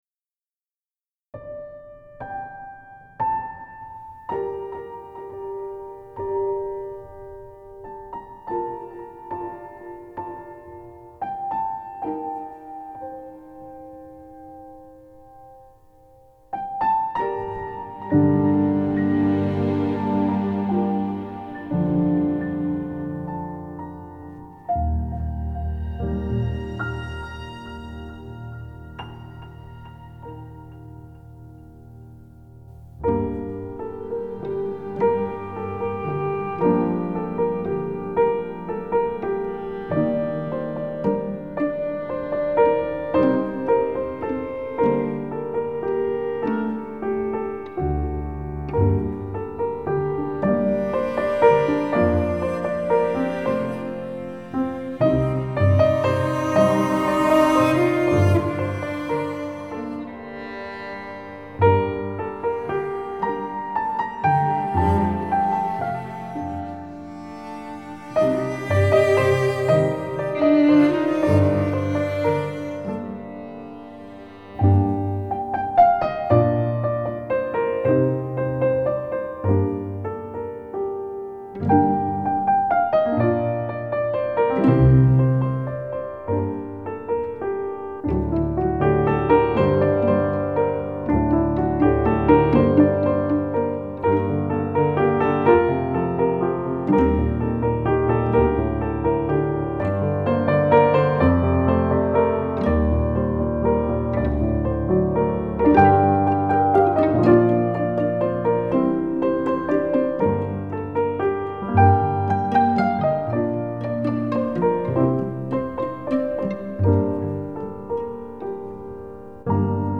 سبک آرامش بخش , پیانو , کلاسیک , موسیقی بی کلام